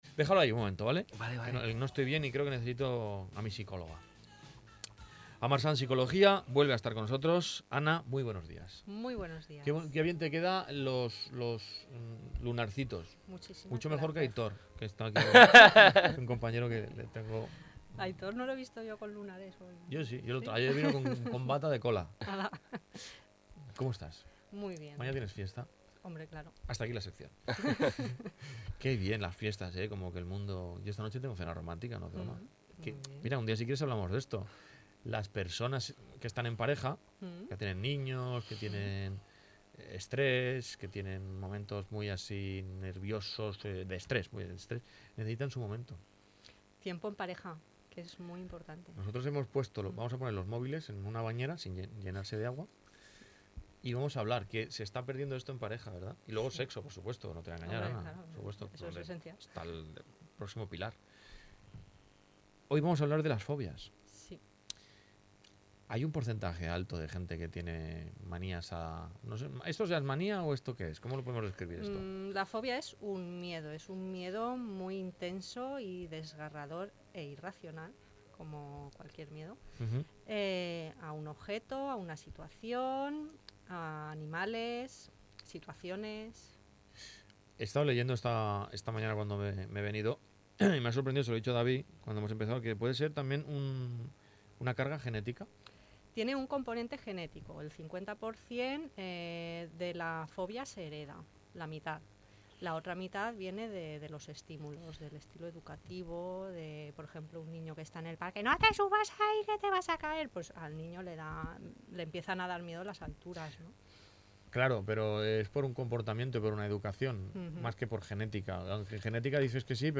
Puedes escuchar la entrevista sobre fobias